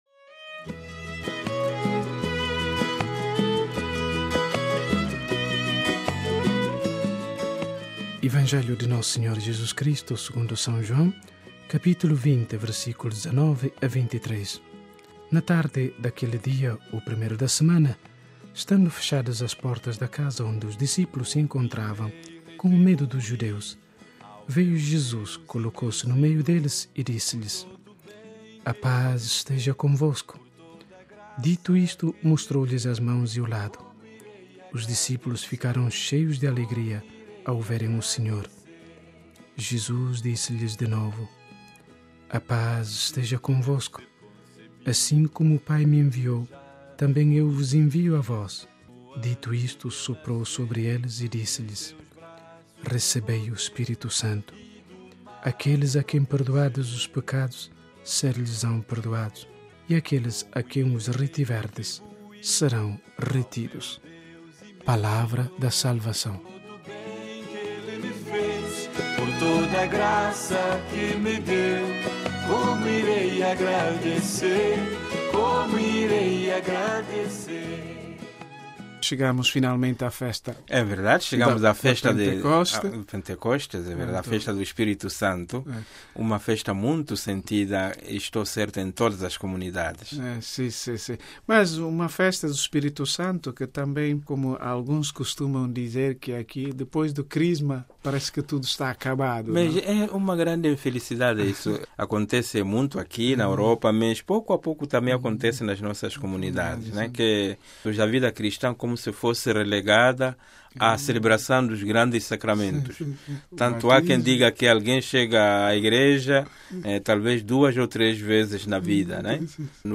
o diálogo